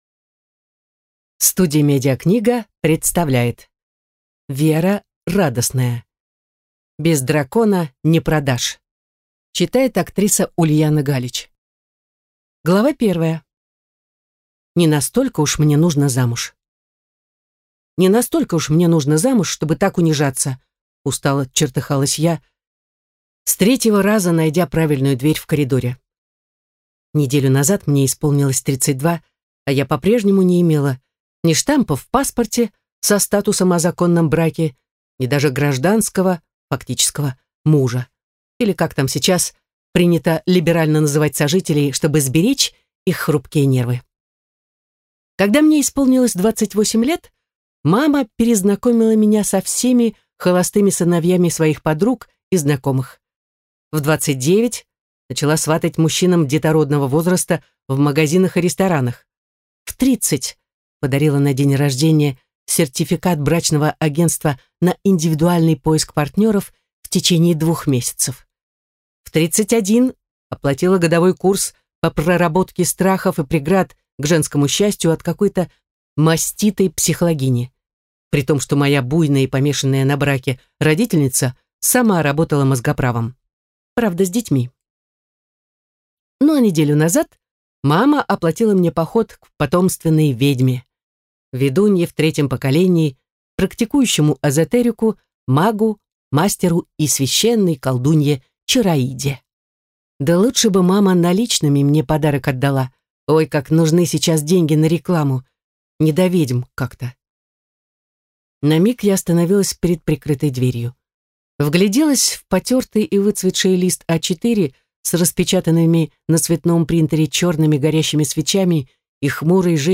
Аудиокнига Без дракона не продашь!